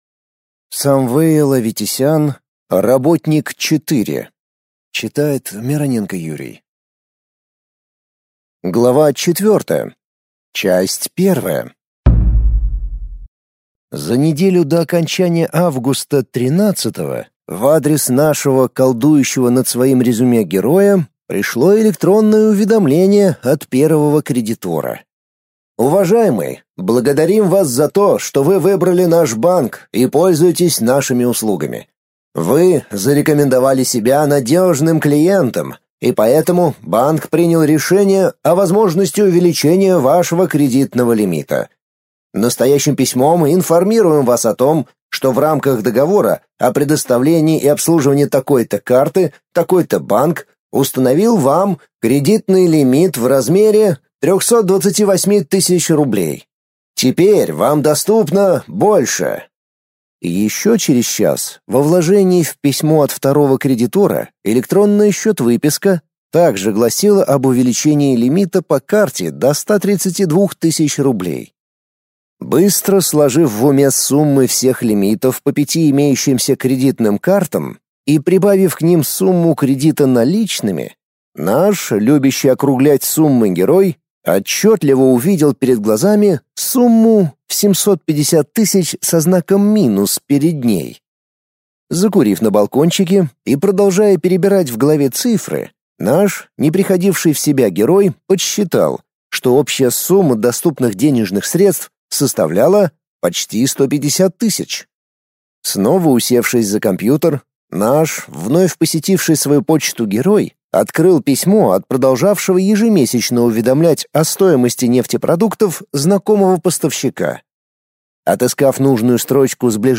Аудиокнига Работник 4 | Библиотека аудиокниг